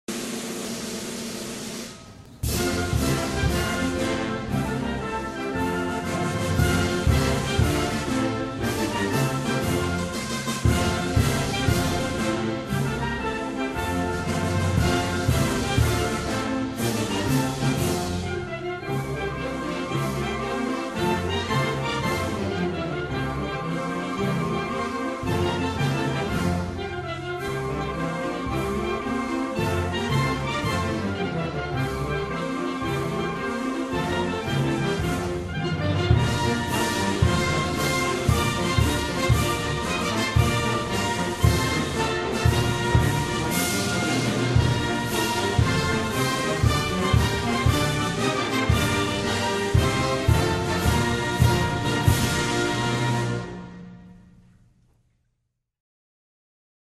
Filipino_anthem.mp3